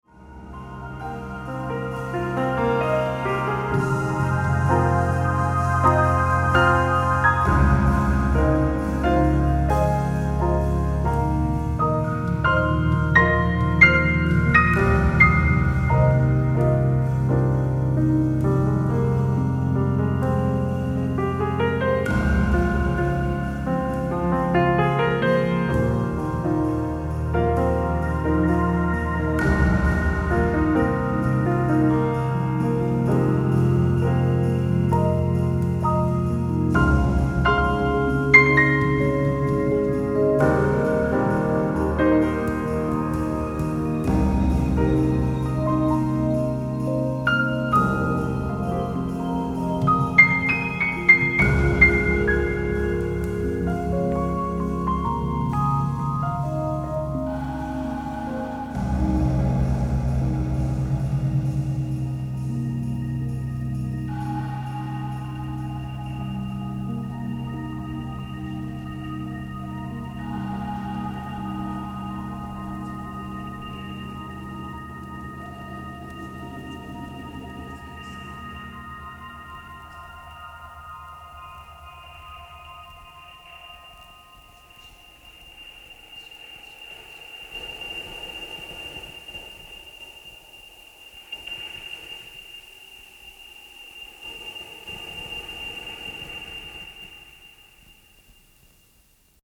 piano, electric piano, electronic keyboards
bass, electronics.
is an instrumental record featuring gorgeous piano ballads